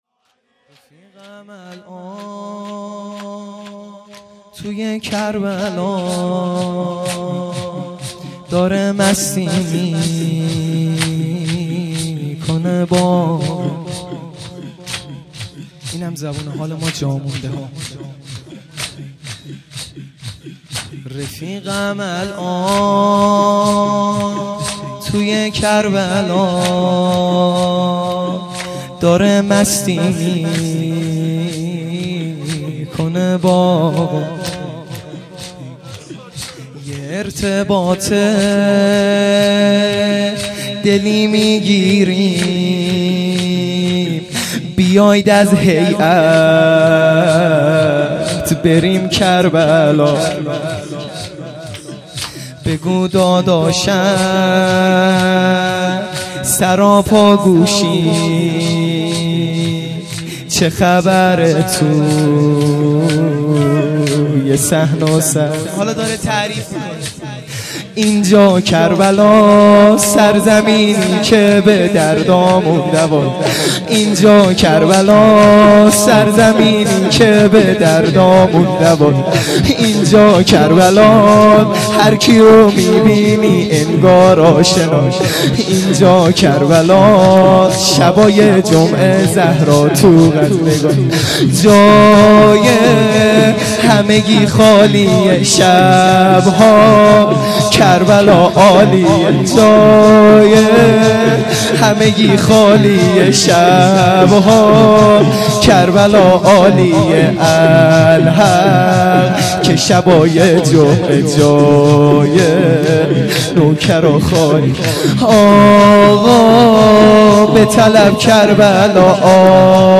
شور | رفیقم الان توی کربلاست